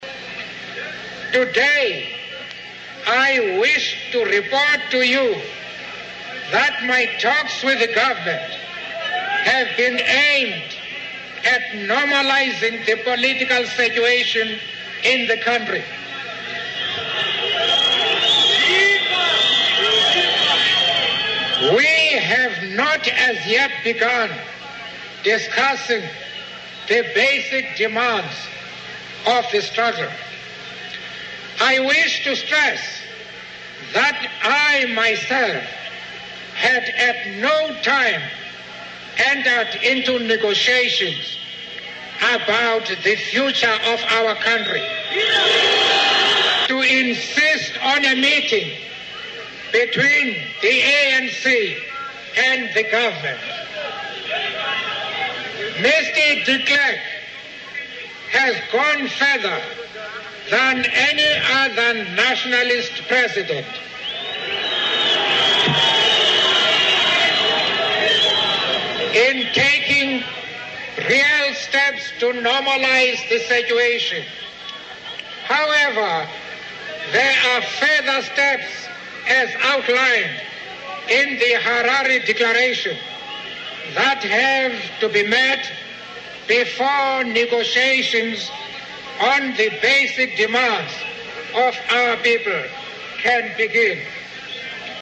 在线英语听力室名人励志英语演讲 第35期:为理想我愿献出生命(9)的听力文件下载,《名人励志英语演讲》收录了19篇英语演讲，演讲者来自政治、经济、文化等各个领域，分别为国家领袖、政治人物、商界精英、作家记者和娱乐名人，内容附带音频和中英双语字幕。